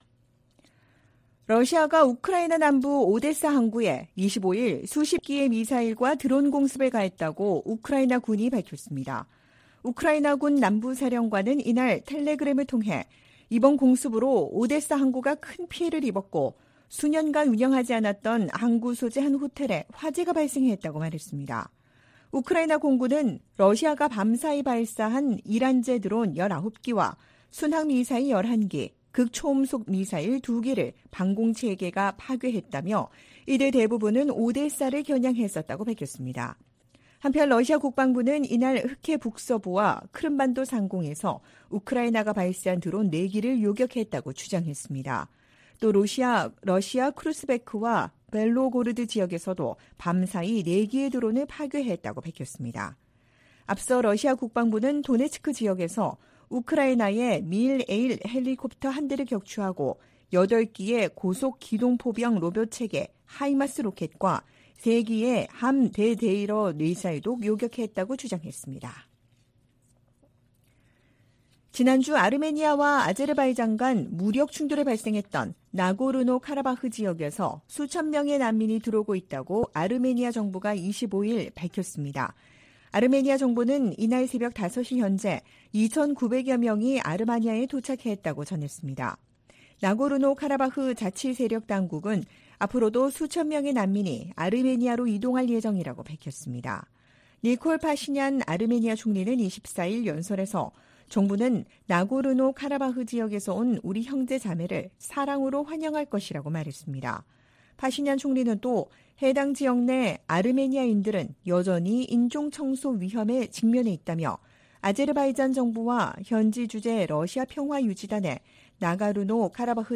VOA 한국어 '출발 뉴스 쇼', 2023년 9월 26일 방송입니다. 조 바이든 미국 대통령은 러시아가 우크라이나의 평화를 가로막고 있다면서 이란과 북한으로부터 더 많은 무기를 얻으려 하고 있다고 비판했습니다. 시진핑 중국 국가주석이 한국 방문 의사를 밝히고 관계 개선 의지를 보였습니다. 미국, 일본, 인도, 호주 4개국이 유엔 회원국에 북한과 무기 거래를 하지 말 것을 촉구했습니다.